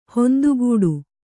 ♪ hondugūḍu